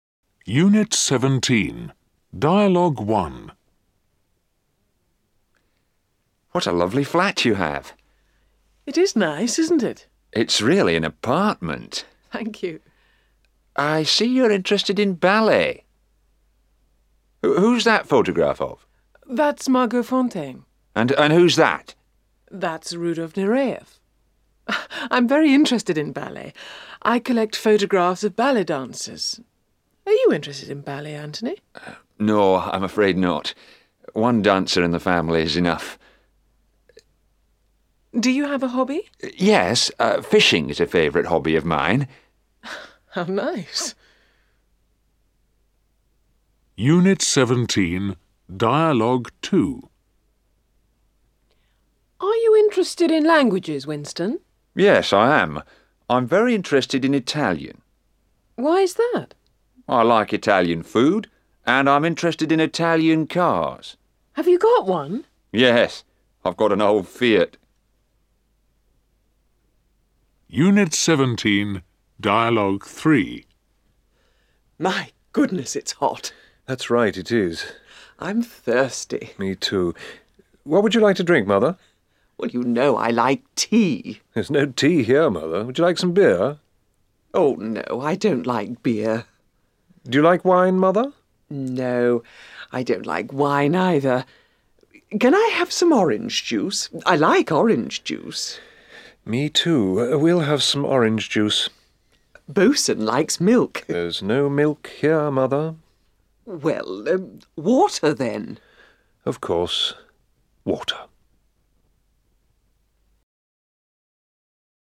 13 - Unit 17, Dialogues.mp3